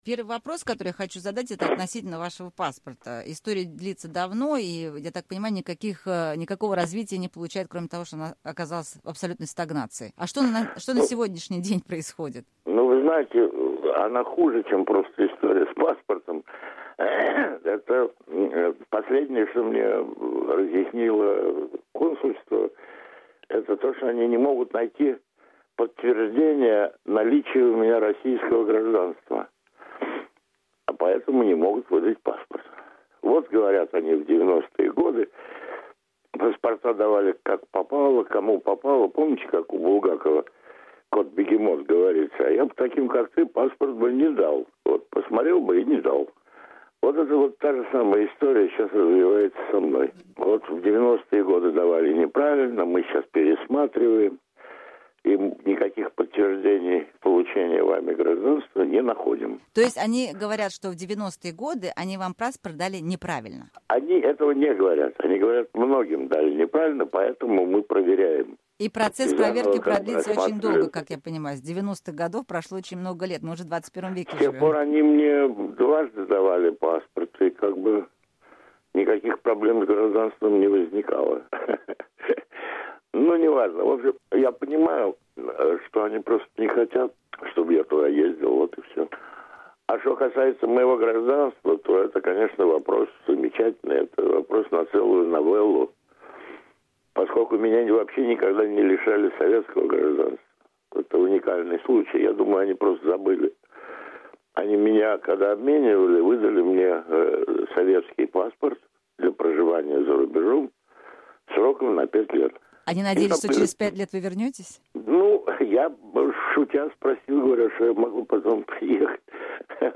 Писатель и диссидент Владимир Буковский в эксклюзивном интервью "Настоящее Время" проясняет историю с российским гражданством